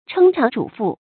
撐腸拄腹 注音： ㄔㄥ ㄔㄤˊ ㄓㄨˇ ㄈㄨˋ 讀音讀法： 意思解釋： 比喻容受很多。